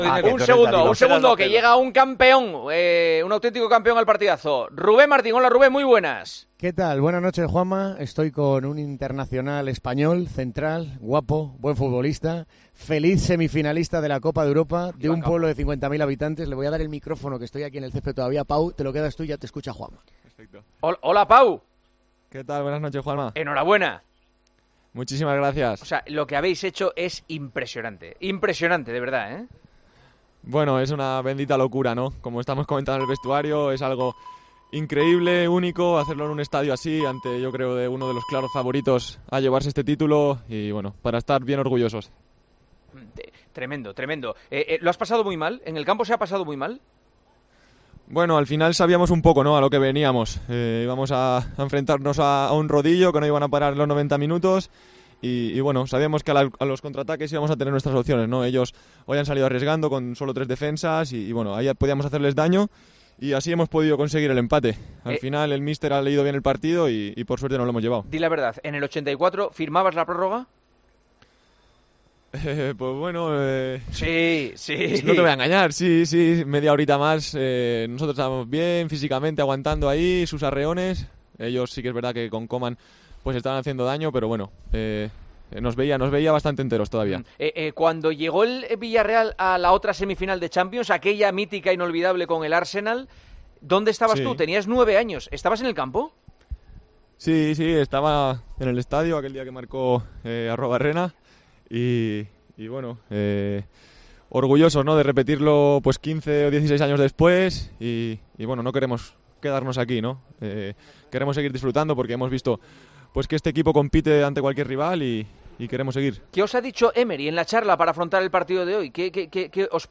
Tras ese partido, Pau Torres, futbolista del Villarreal, ha estado con Juanma Castaño en El Partidazo de COPE.